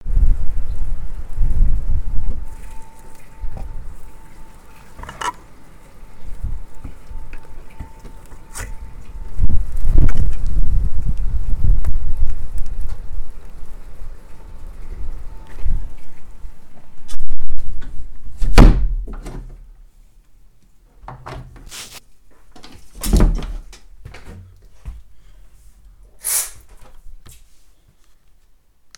file0235-water
Category 🌿 Nature
bath bubble burp click drain dribble drip drop sound effect free sound royalty free Nature